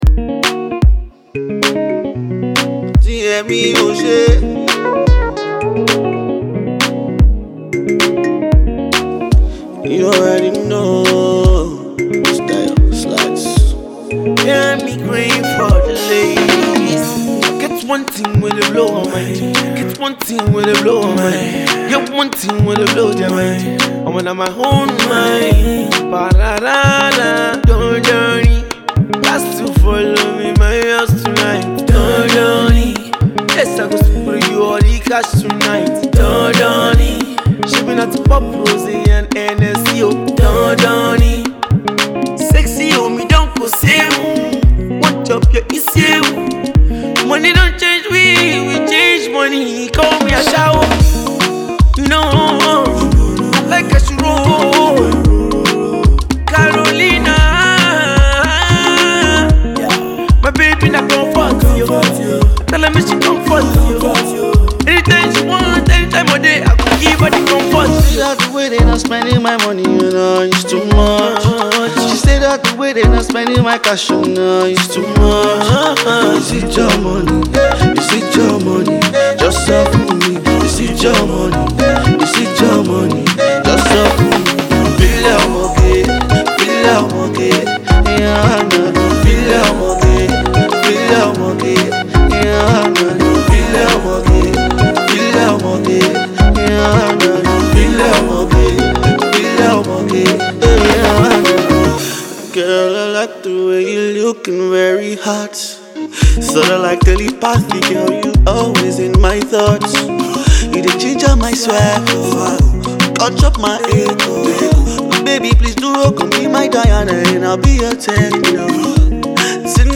soft groovy tune